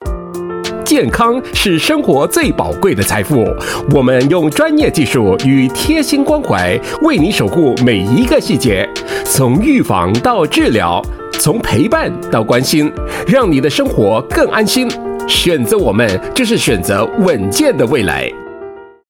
Voice Samples: Health / Medical
male